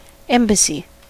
Ääntäminen
US
IPA : /ˈɛmbəsi/